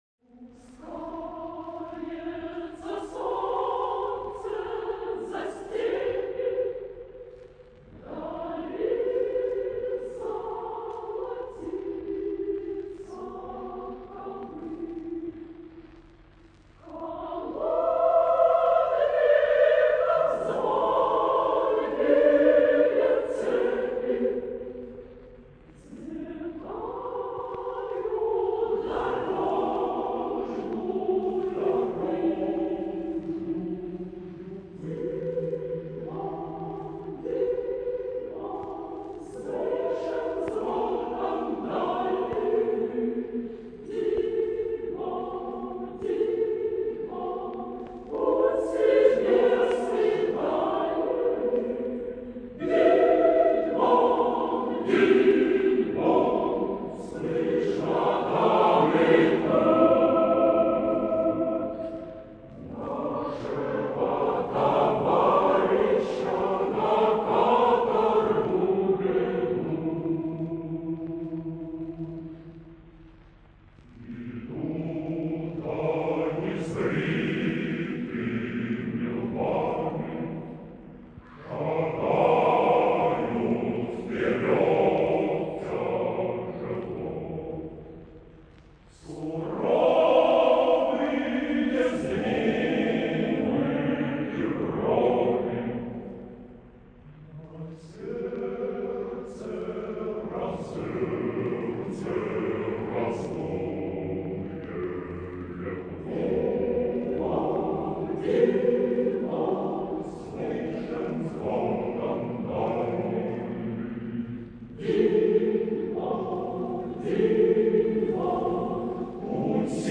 Описание: Классическое хоровое исполнение. Качество хорошее